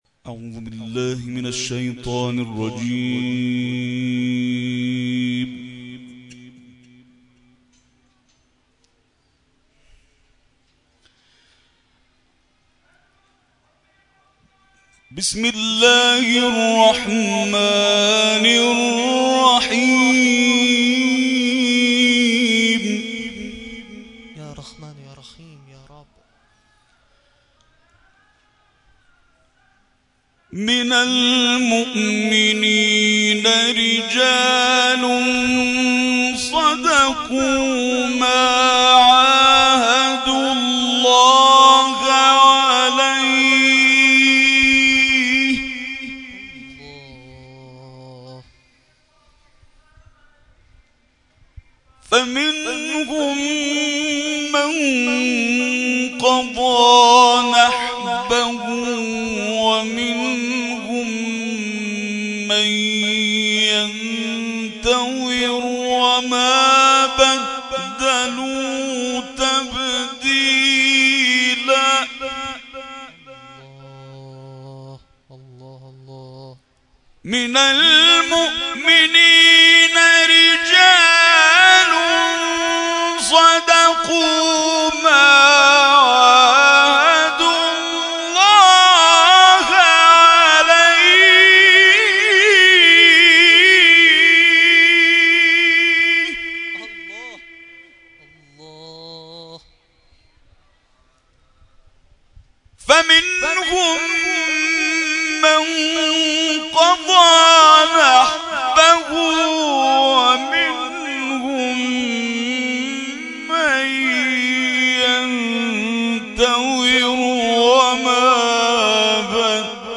قاری بین‌المللی کشورمان در شامگاه شام غریبان، در تکیه تجریش به تلاوت آیاتی از کلام الله مجید پرداخت.
در این مراسم علاوه بر مرثیه‌خوانی ذاکران اهل بیت عصمت و طهارت، قاریان ممتاز و بین‌المللی کشورمان نیز به تلاوت پرداختند.